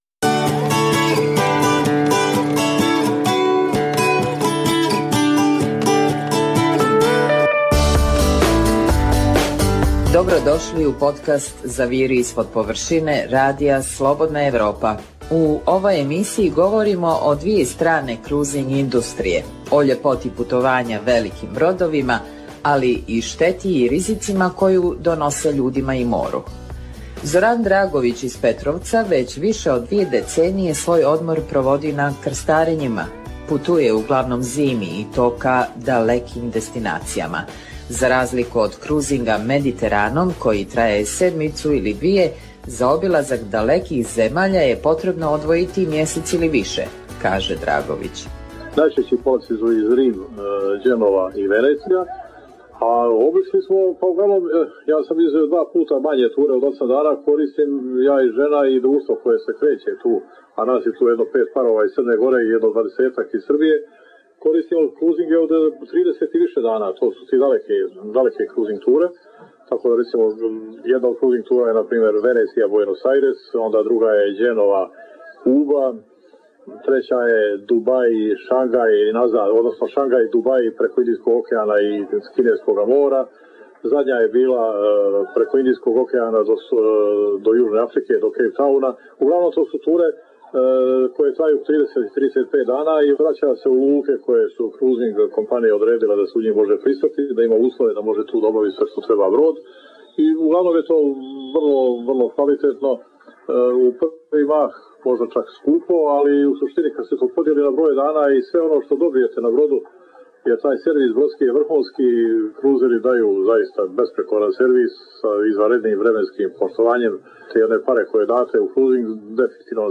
Preostalih pola sata emisije, nazvanih "Tema sedmice" sadrži analitičke teme, intervjue i priče iz života, te rubriku "Dnevnik", koji poznate i zanimljive osobe vode za Radio Slobodna Evropa vode